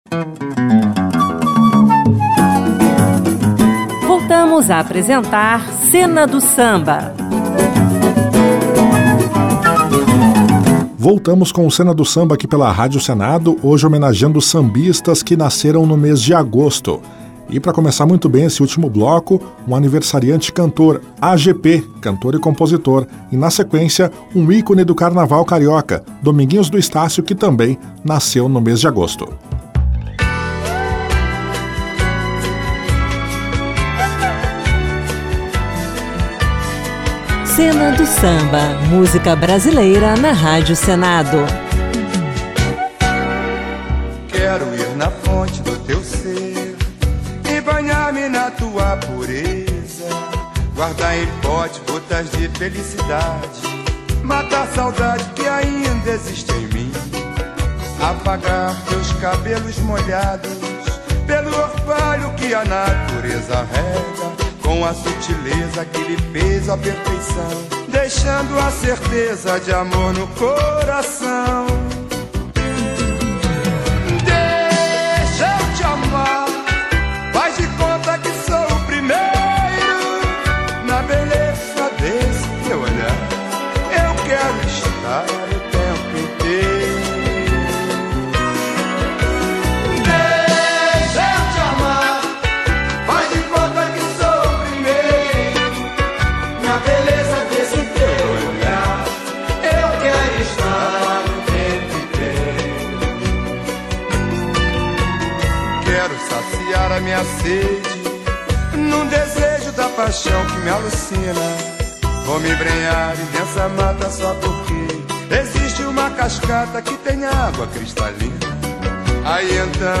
clássicos do samba